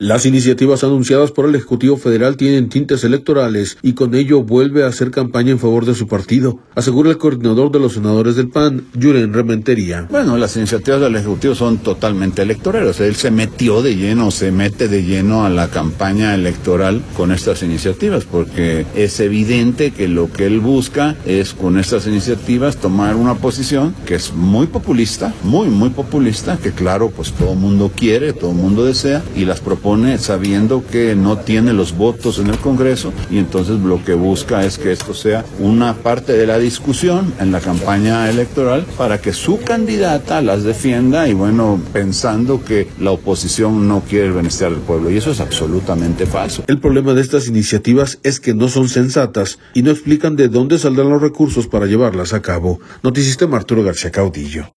Las iniciativas anunciadas por el Ejecutivo Federal tienen tintes electorales y con ello vuelve a hacer campaña en favor de su partido, asegura el coordinador de los senadores del PAN, Julen Rementería.